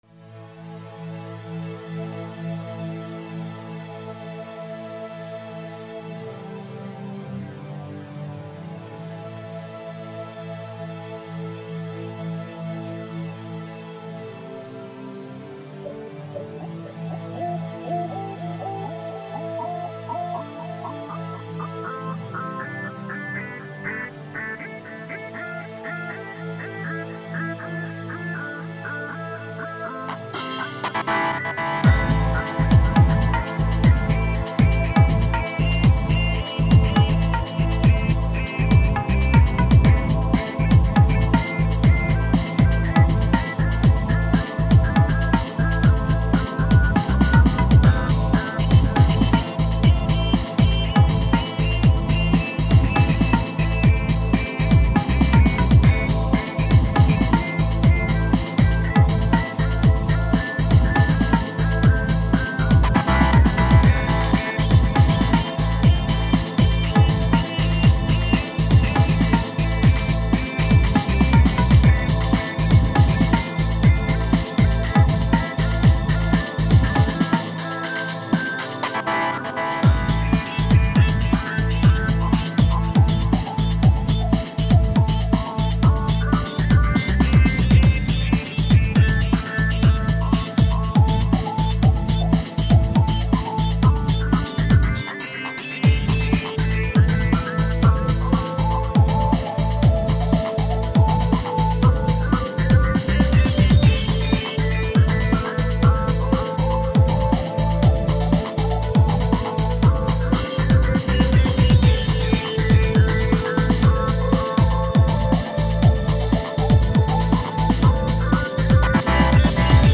...elektronischer Art
Kein audiophiler Hörgenuss, nur just4fun :)